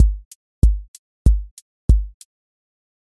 QA Test — four on floor
Open MP3 directly Selected Components macro_house_four_on_floor voice_kick_808 voice_hat_rimshot voice_sub_pulse Test Notes What This Test Is Four on floor Selected Components macro_house_four_on_floor voice_kick_808 voice_hat_rimshot voice_sub_pulse